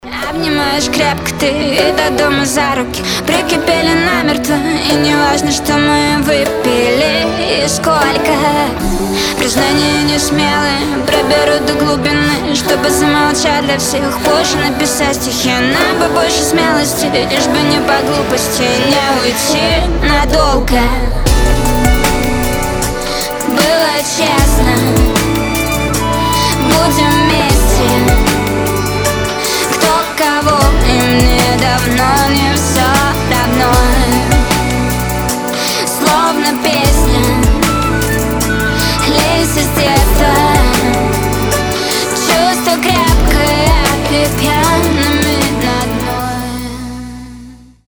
поп
мелодичные
чувственные
Chill Trap
Флейта
духовые